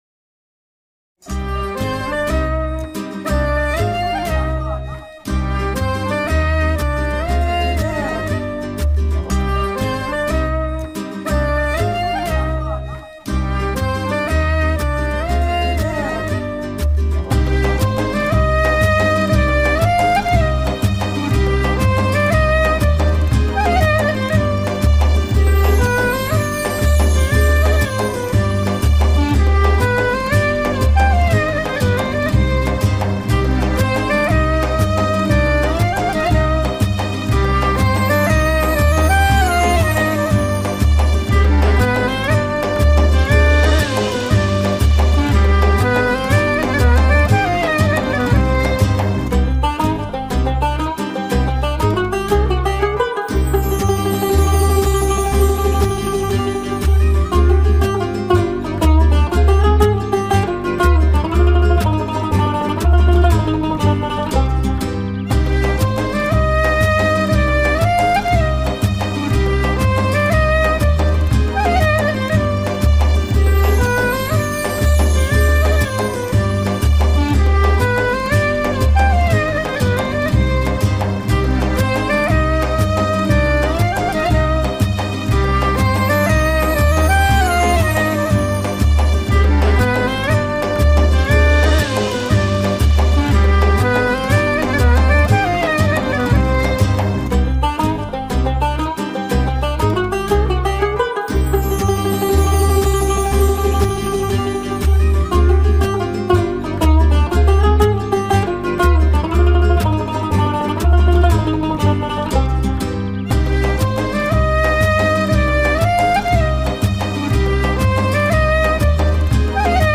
neşeli eğlenceli enerjik fon müziği.